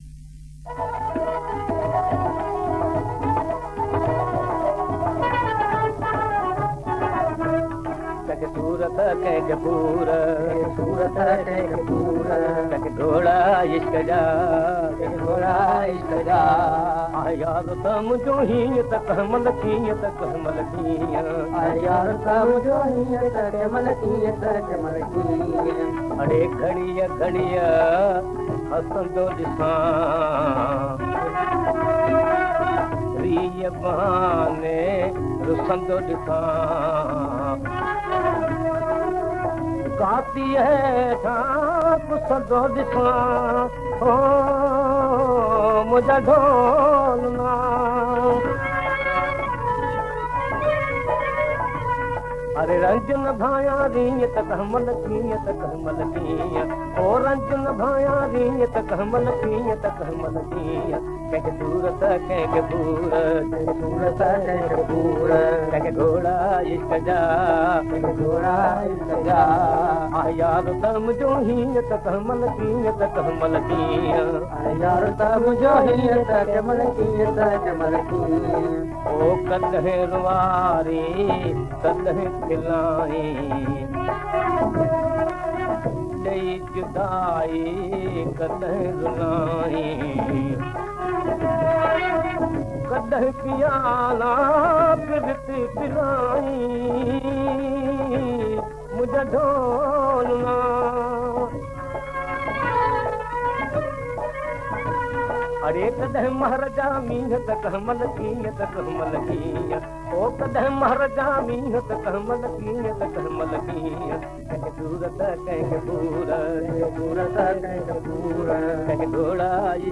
Converted from very old Gramophone records.